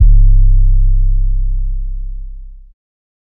808 (Dubai Shit).wav